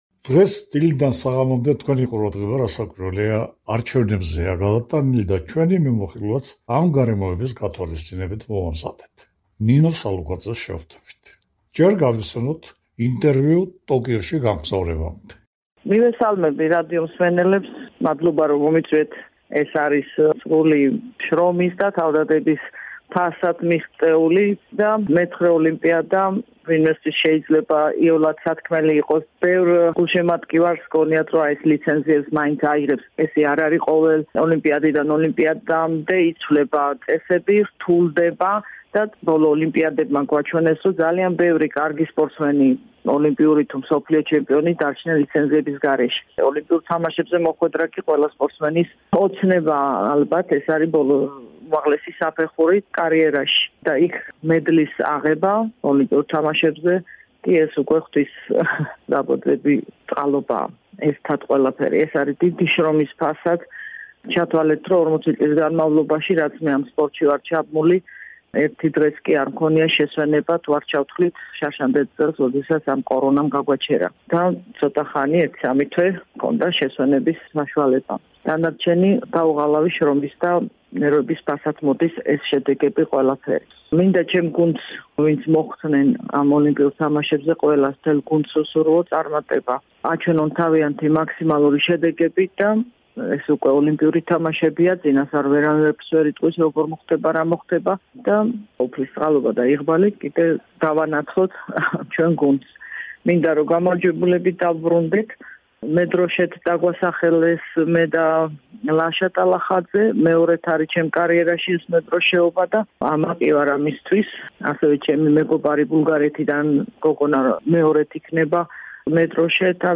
ამ სიტყვებით ამთავრებს ნინო სალუქვაძე ინტერვიუს რადიო თავისუფლებასთან. სახელოვანი სპორტსმენი მეცხრედ გამოვიდა ოლიმპიურ თამაშებზე, ტოკიოშივე გამოაცხადა ასპარეზიდან წასვლის გადაწყვეტილება, თუმცა ახლა, ოლიმპიადამდე პერიოდი პრობლემების გახსენებისას, როცა გაწბილების განცდაც ერთგვარად განელდა -...